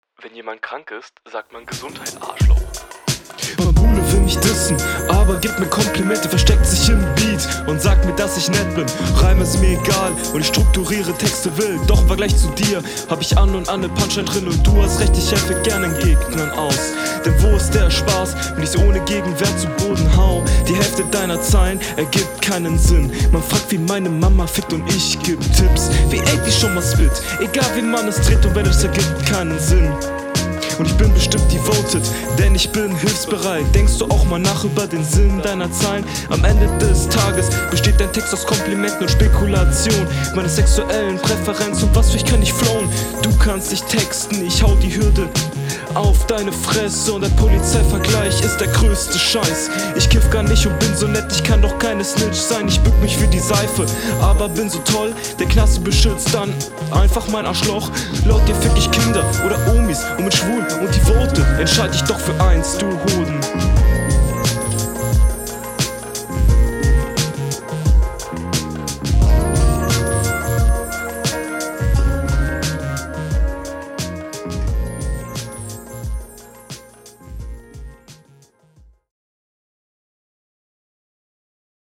Du probierst hier flowlich Dinge zu machen, die leider nicht ganz funktionieren, die kurzen Shuffle-Ansätze …